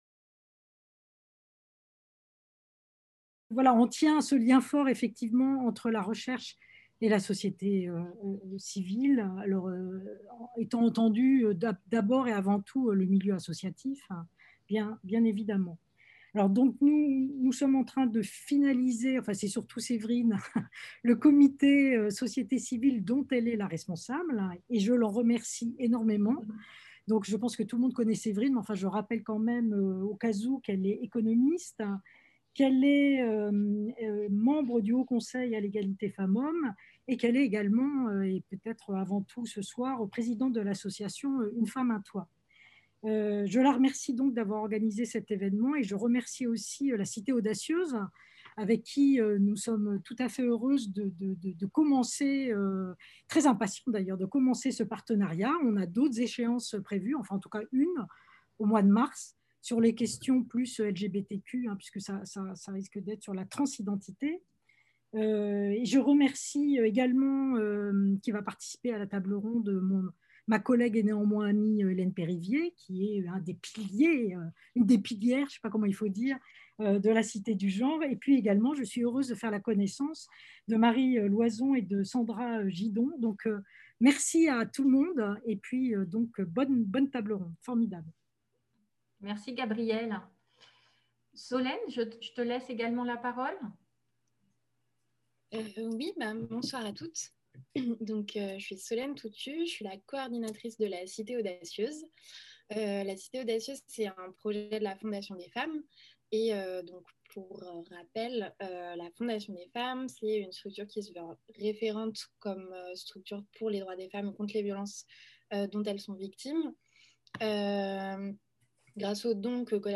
Cet événement a été organisé en collaboration avec la Cité Audacieuse. L'objectif est de créer un lien entre la recherche en études de genre et la société civile qui agit sur le terrain contre les inégalités et les violences se